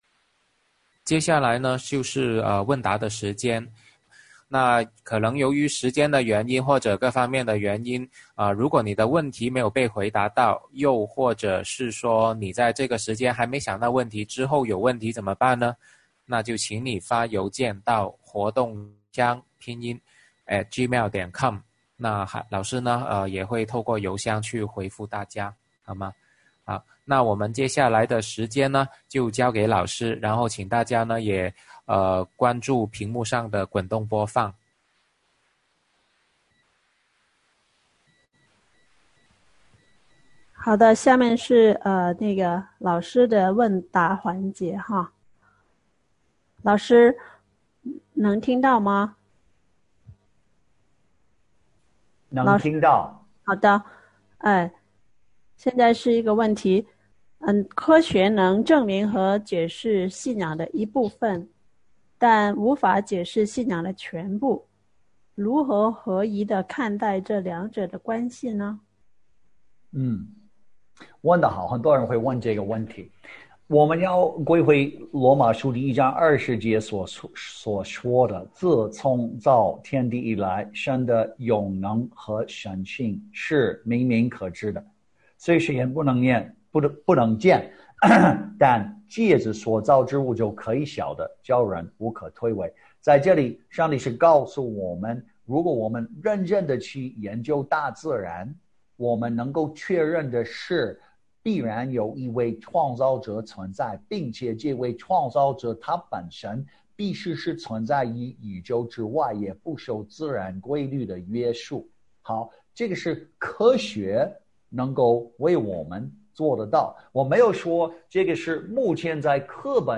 《创世记：传福音的关键》讲座直播回放